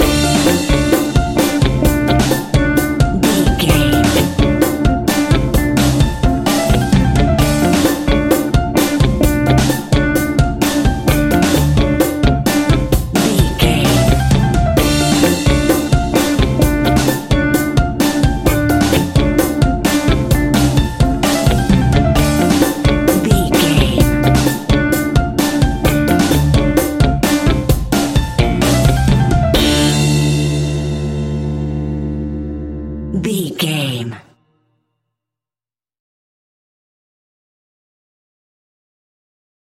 Aeolian/Minor
flamenco
salsa
maracas
percussion spanish guitar
latin guitar